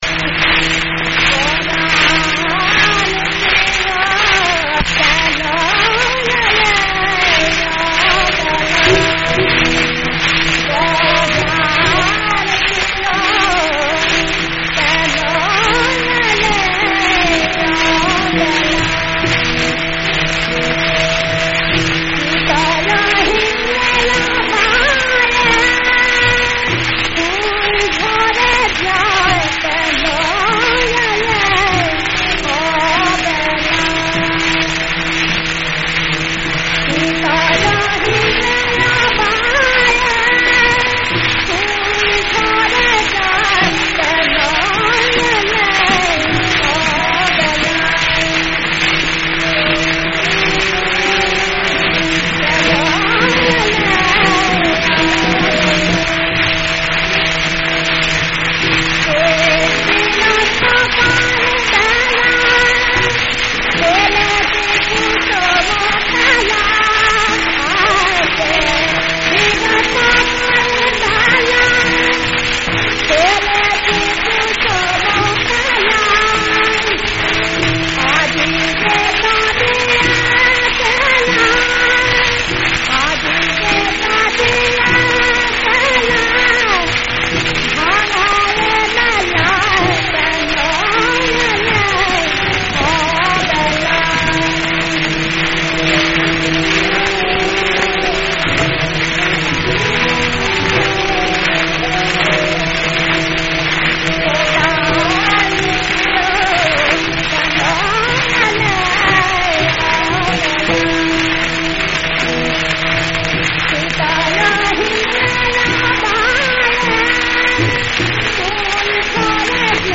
রাগ: পিলু. তাল: দাদ্‌রা
গানটির রাগ পিলু।
• তাল: দাদরা
• গ্রহস্বর: সা